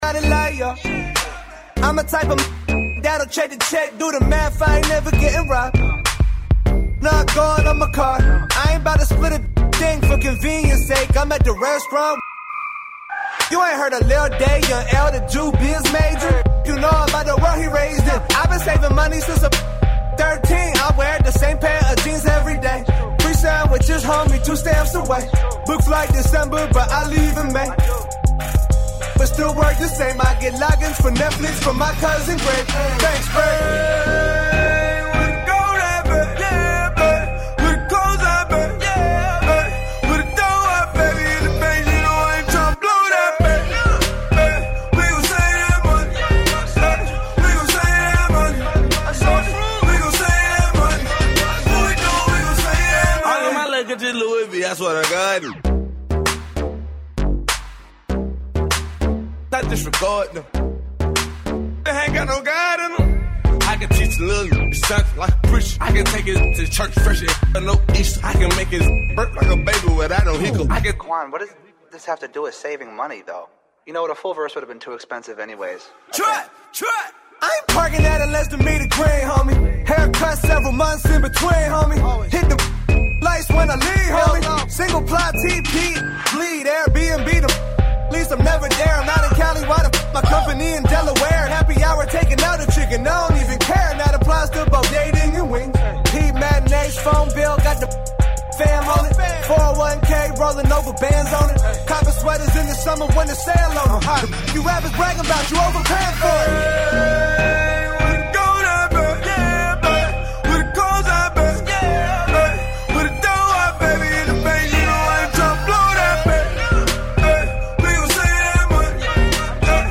Youth Radio Raw is a weekly radio show produced by Bay Area high schoolers, ages 14-18.